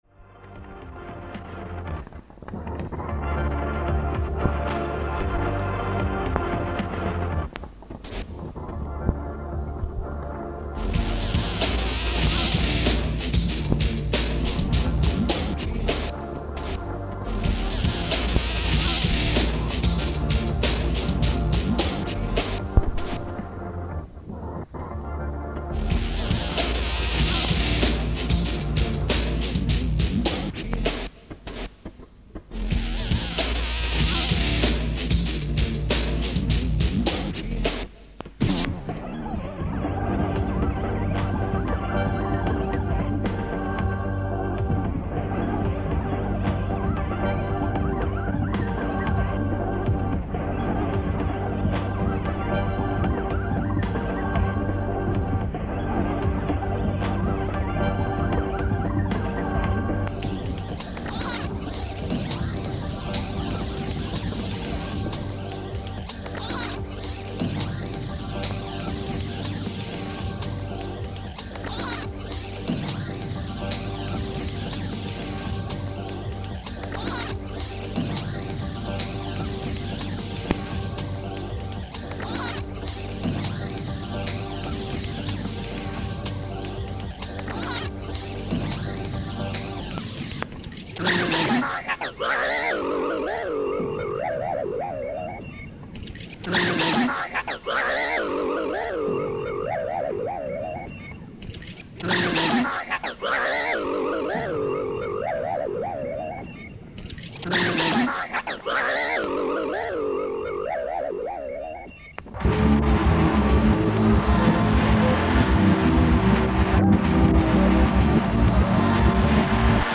noise, dark ambient, hometaping